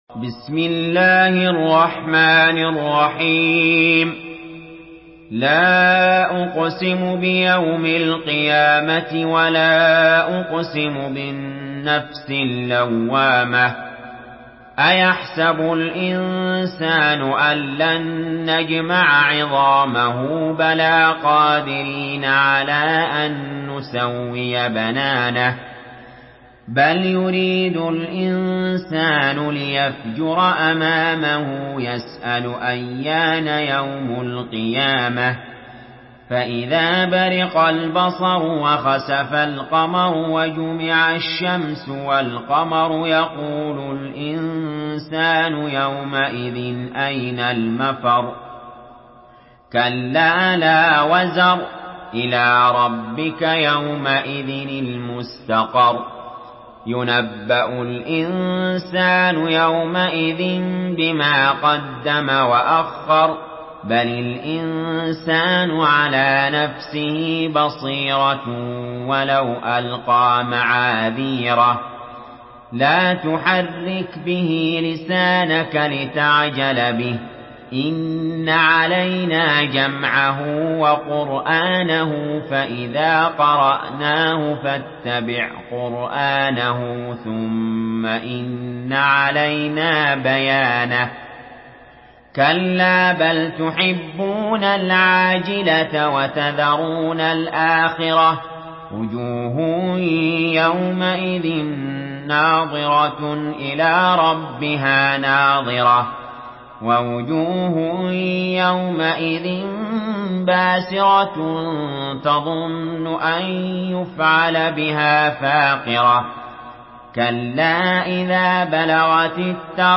Surah Al-Qiyamah MP3 by Ali Jaber in Hafs An Asim narration.
Murattal Hafs An Asim